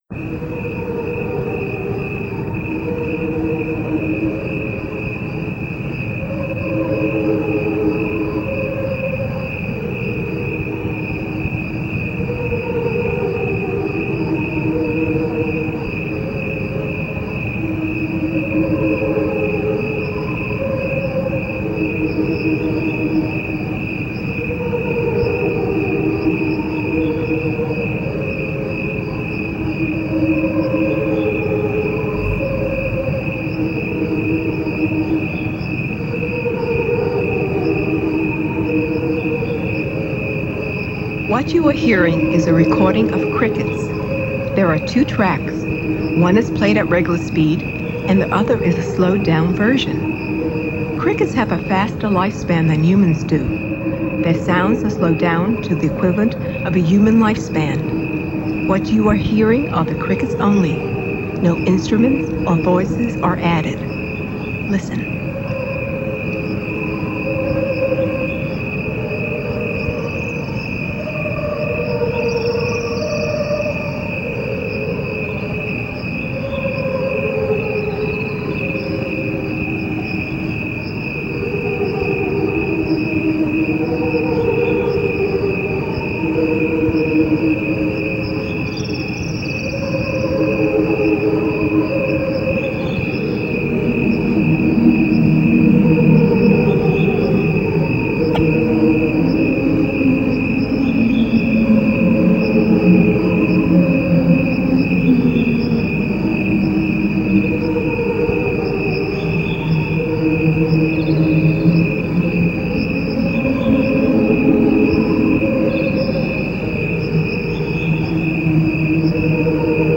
gods-choir-of-crickets.mp3